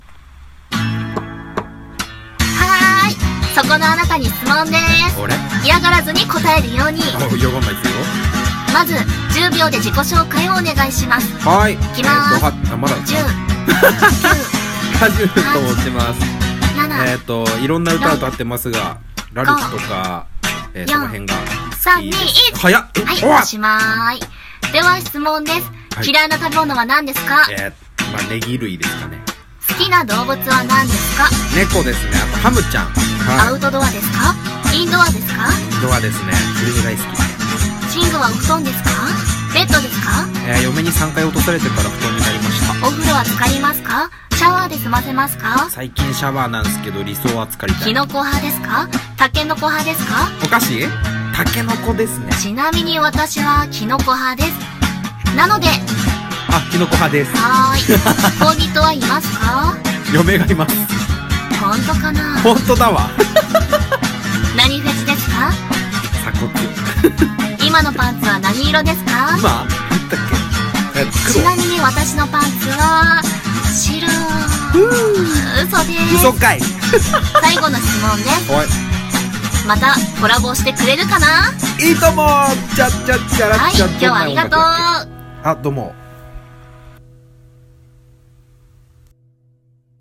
【二人声劇】 質問です！